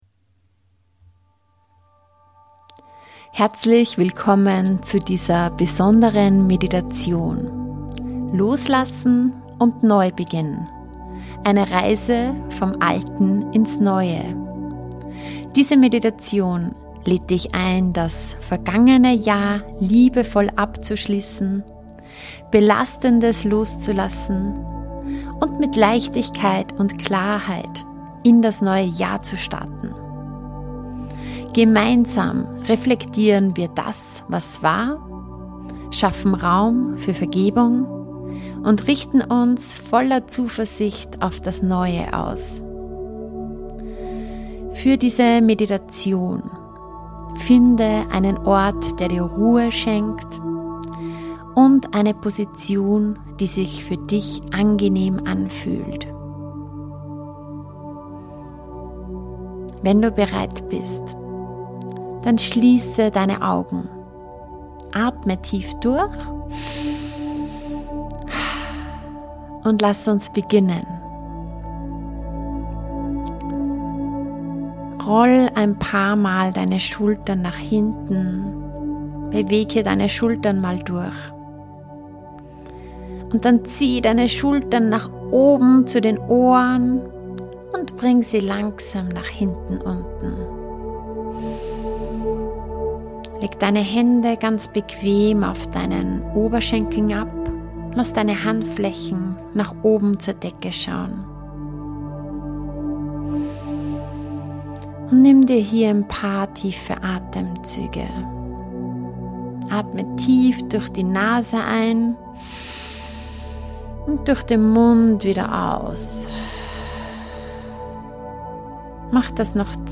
Für einen klaren Geist und ein leichtes Herz: Deine Meditation zum Jahreswechsel In dieser besonderen Meditation begleite ich dich auf einer Reise, um das vergangene Jahr liebevoll abzuschließen, Belastendes loszulassen und voller Klarheit und Zuversicht in das neue Jahr zu starten.